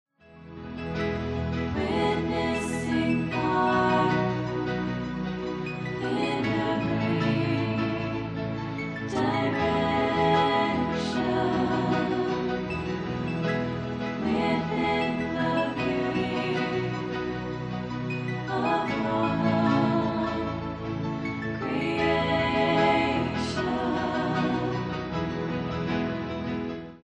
Her style ranges between New Age and Electronic.
This CD is a live recording.